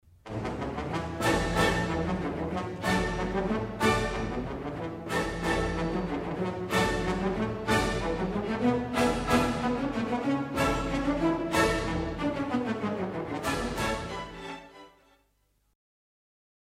tromboni in orchestra
tromboni_1.mp3